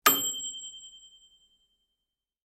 Звуки микроволновой печи
Звук дзынь микроволновки когда еда готова и время закончилось